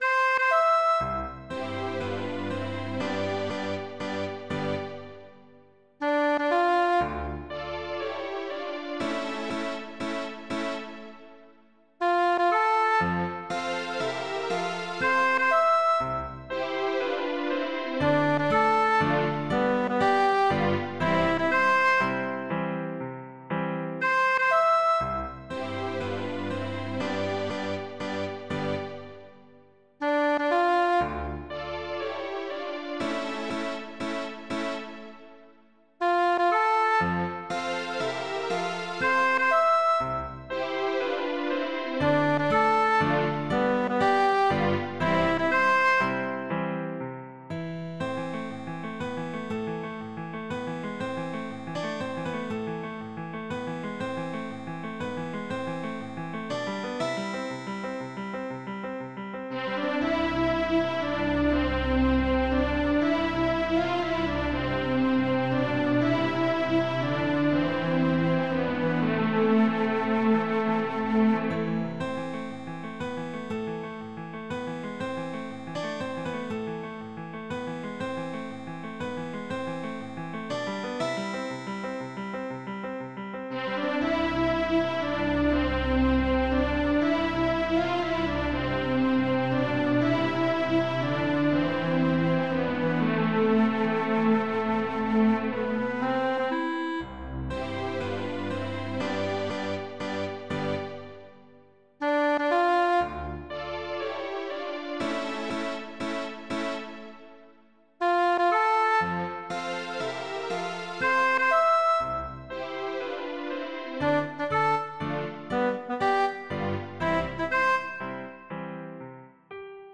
ASCOLTA E SCARICA GRATUITAMENTE LE DEMO
per orchestra scolastica